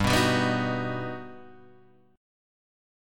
G Augmented Major 7th
G+M7 chord {3 2 5 4 4 2} chord